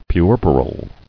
[pu·er·per·al]